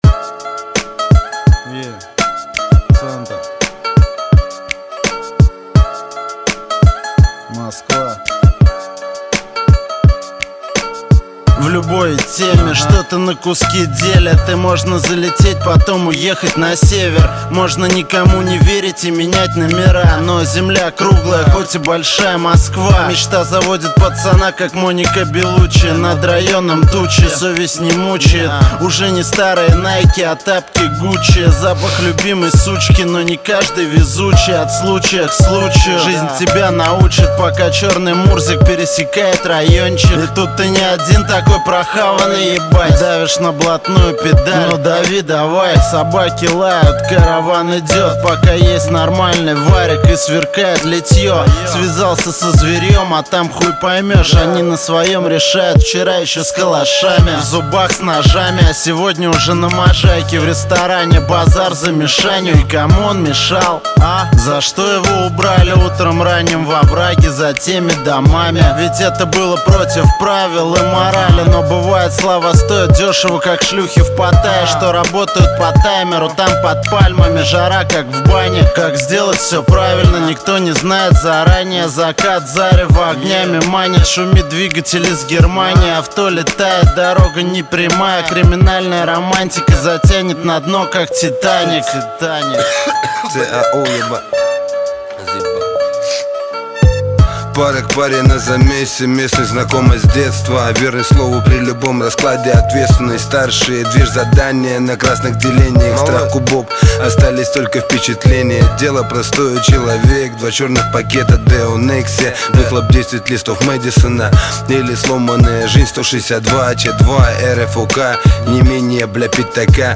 Жанр: Рэп (Хип-хоп)